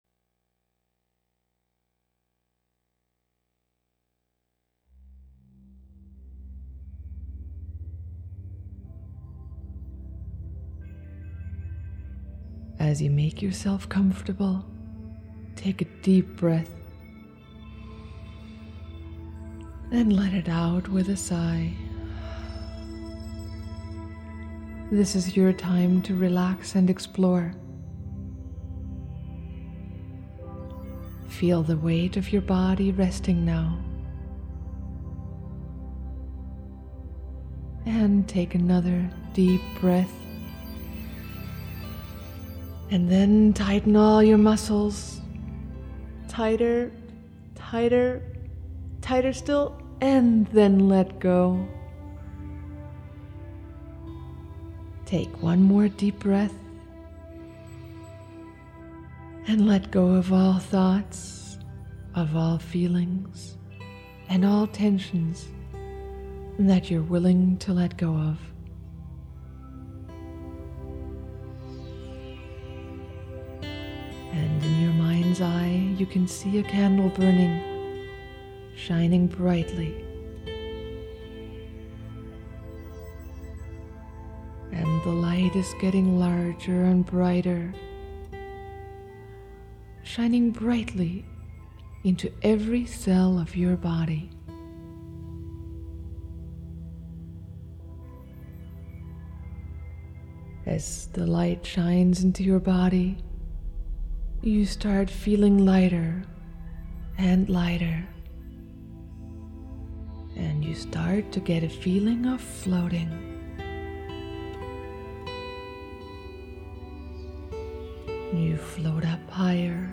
Guided Meditation to Strengthen your Mental, Emotional & Spiritual Wellbeing